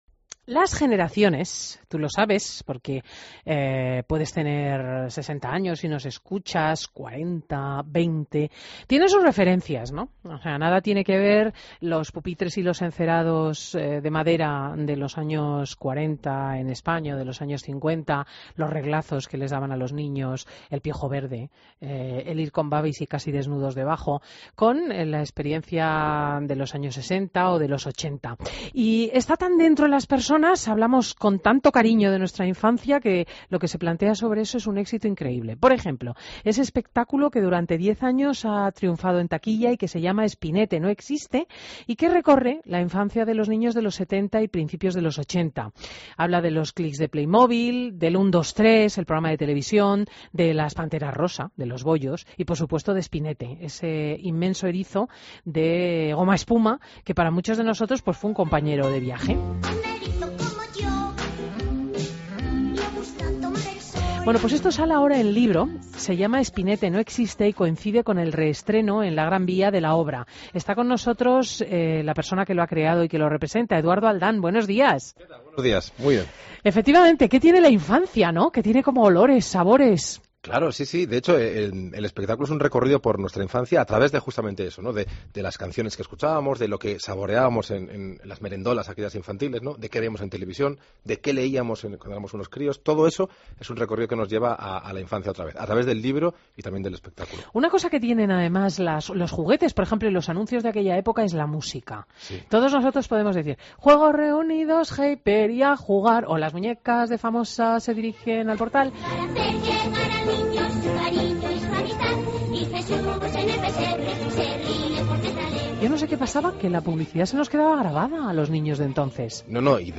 AUDIO: Entrevista a Eduardo Aldán en Fin de Semana COPE